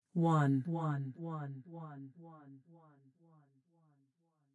描述：女声说“一”。